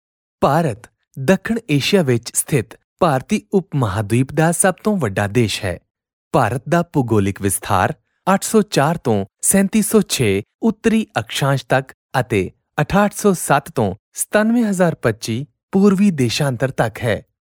Male
Teenager (13-17), Adult (30-50)
BASE - FRIENDLY - CLEAR
Hindi Vo Demo Reel